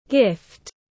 Gift /ɡɪft/